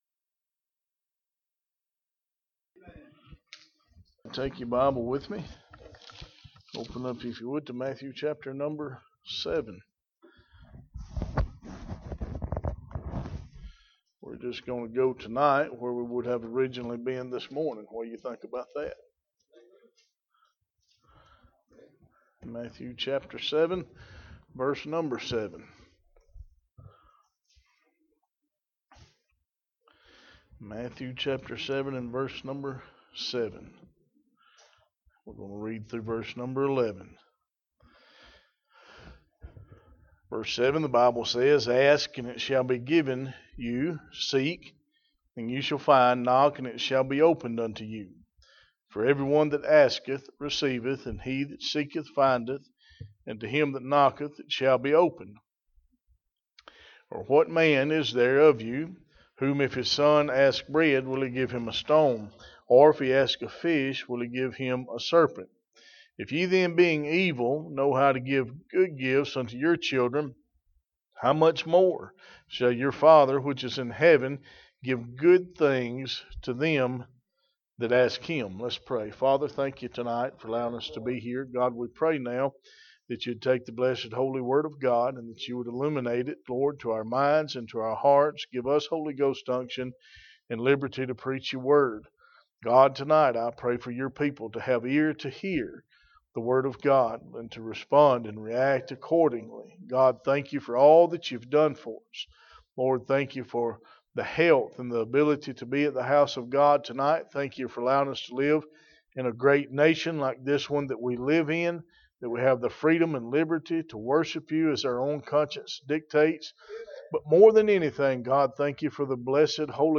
Sermon on the Mount Passage: Matthw 7:7-11 Service Type: Sunday Evening « Who Are You Going To Serve When you Can’t Find God